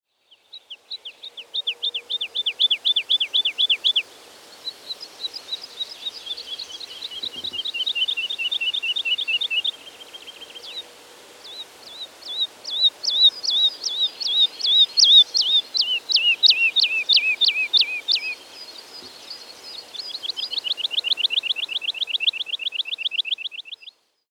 Wer singt oder ruft hier?
Vogel 12
Die Tierstimmen sind alle aus dem Tierstimmenarchiv des Museum für Naturkunde - Leibniz-Institut für Evolutions- und Biodiversitätsforschung an der Humboldt-Universität zu Berlin
MH12_Vogel12.mp3